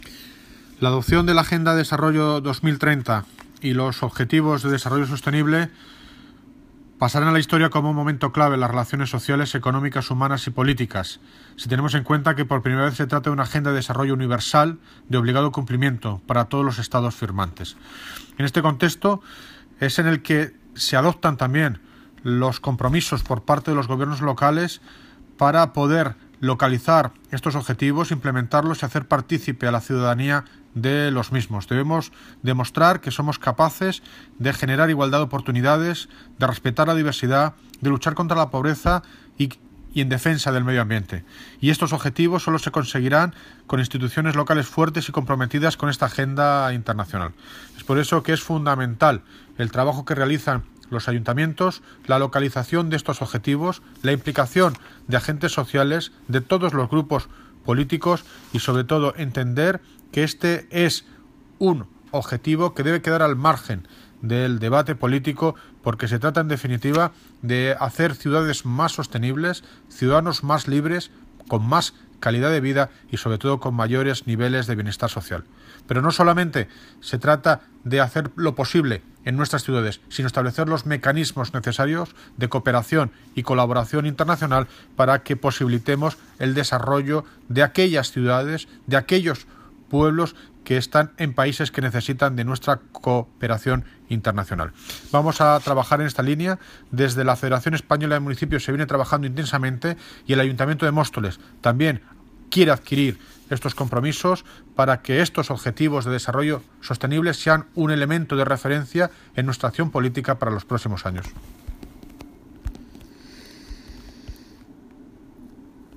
Noticia Fecha de publicación: 3 de octubre de 2017 El Alcalde de Móstoles ha intervenido esta mañana en una ponencia en la que ha explicado las estrateg...